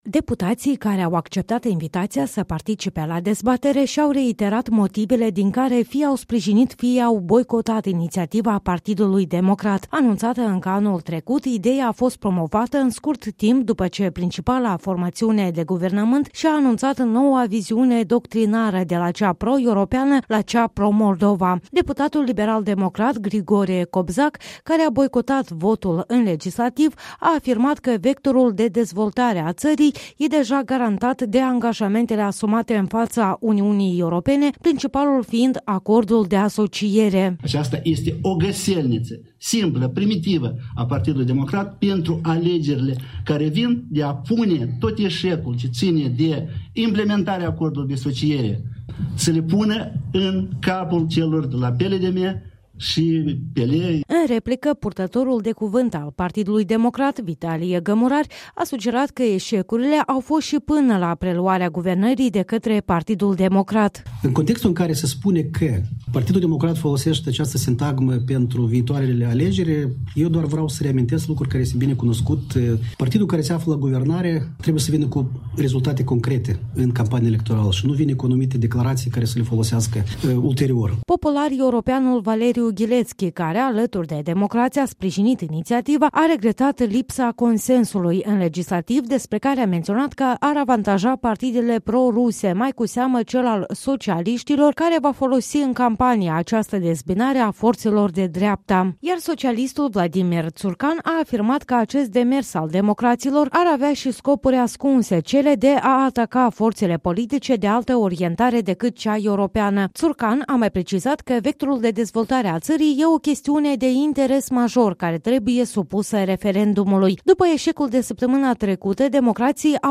Dezbatere la sediul agenției IPN de la Chișinîu